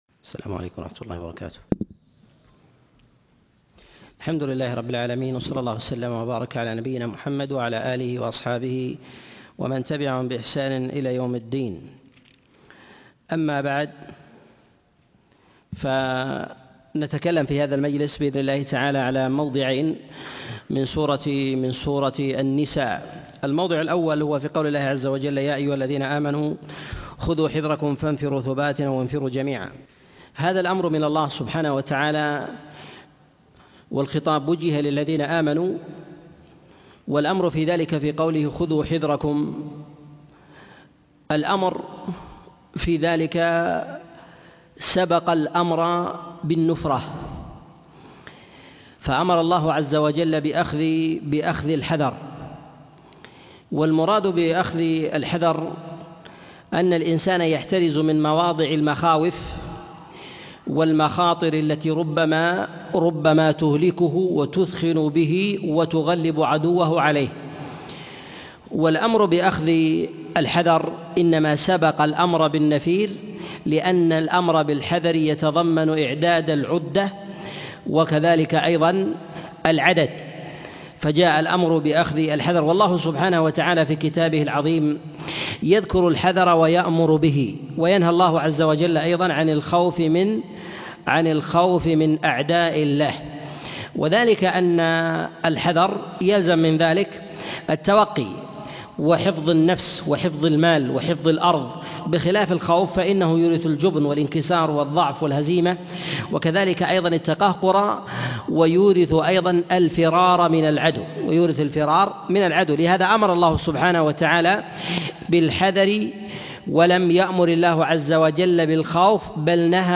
تفسير سورة النساء 17 - تفسير آيات الأحكام - الدرس الواحد والسبعون - الشيخ عبد العزيز بن مرزوق الطريفي